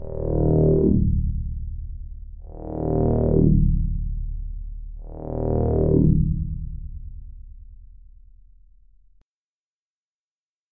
Звуки приближающейся опасности
Нечто необычное надвигается прямо на нас